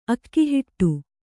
♪ akkihiṭṭu